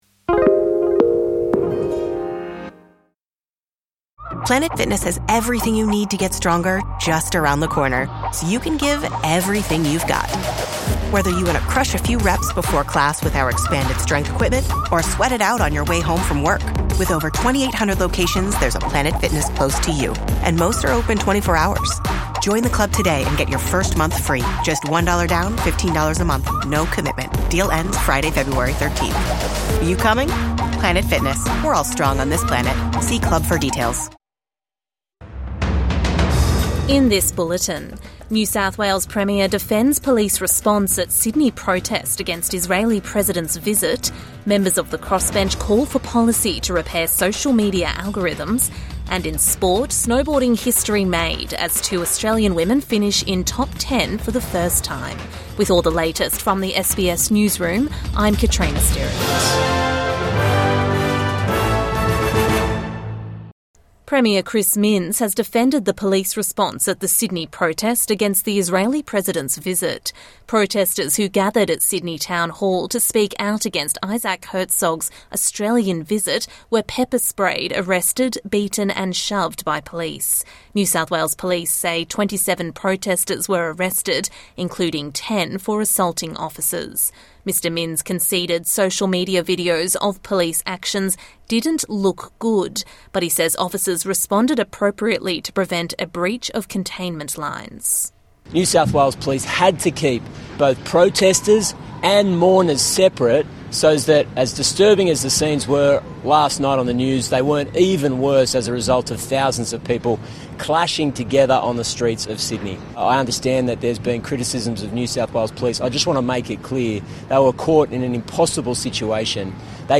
NSW Premier defends police actions at Herzog protest | Midday News Bulletin 10 February 2026